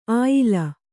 ♪ āyila